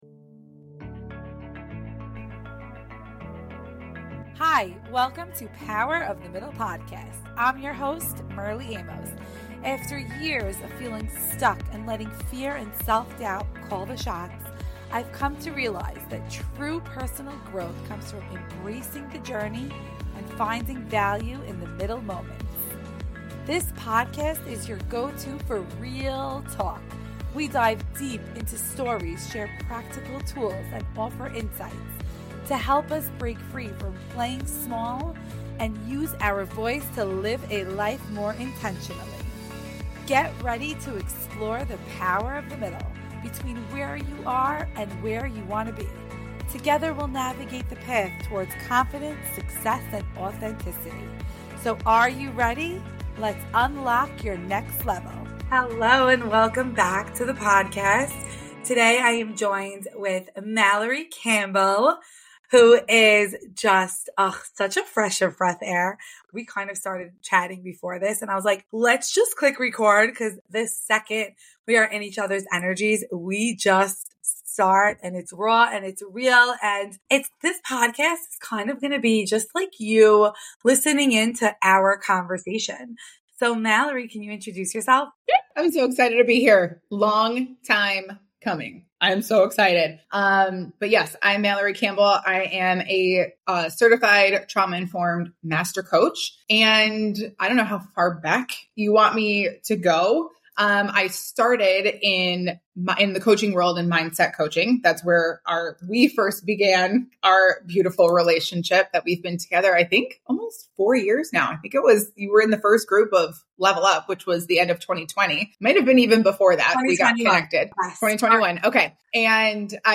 Peeling the Layers to Your Authentic Self: A Conversation